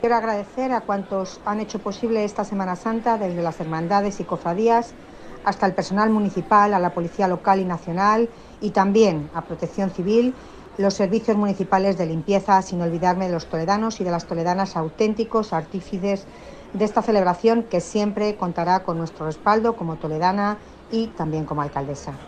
AUDIOS. Milagros Tolón, alcaldesa de Toledo
milagros-tolon_semana-santa_2.mp3